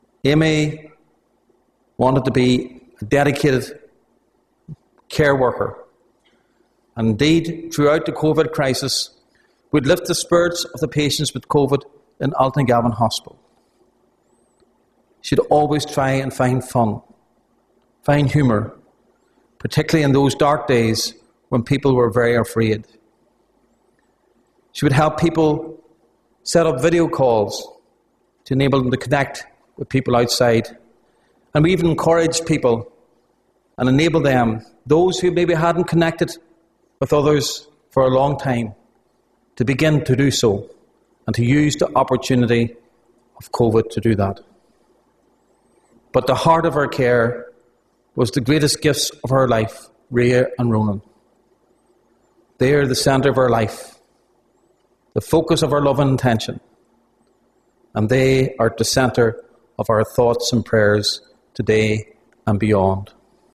funeral-1pm.mp3